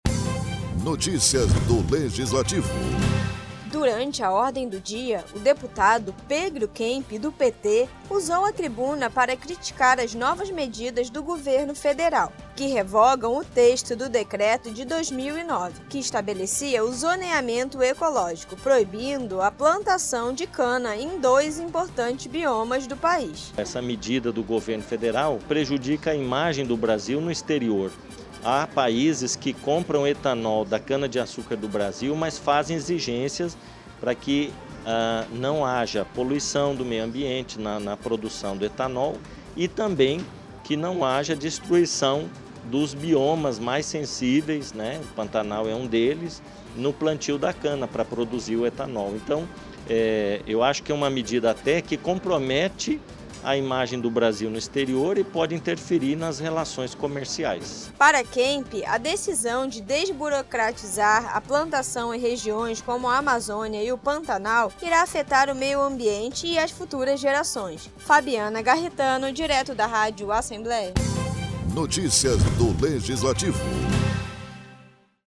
O deputado Pedro Kemp, do PT utilizou a tribuna para analisar o decreto assinado no começo deste mês, e as últimas medidas anunciadas pelo Governo Federal.